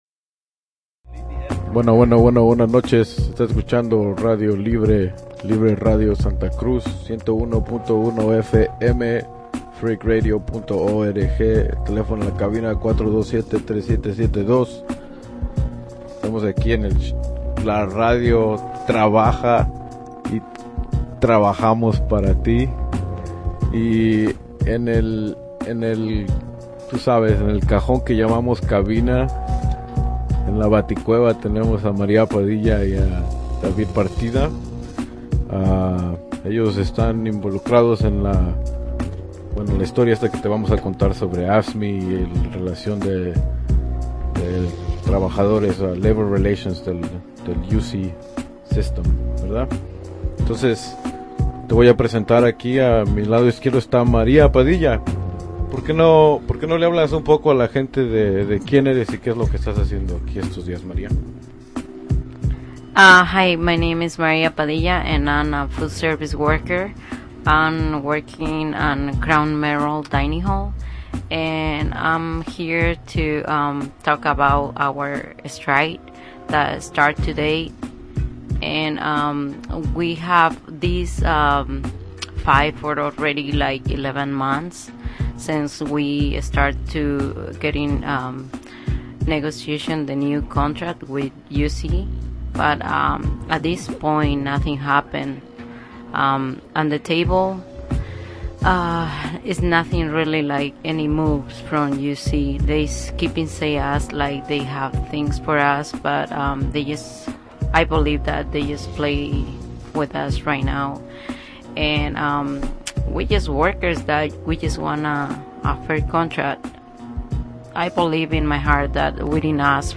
On Monday July 14th students, workers, and supporters from the University of Californina Santa Cruz stopped by Free Radio Santa Cruz to help provide coverage about the first day of the 5 day UC-wide strike. 8,500 University of California workers represented by the American Federation of State, County, and Municipal Employees (AFSCME) began a five-day strike at UC’s ten campuses and five medical centers.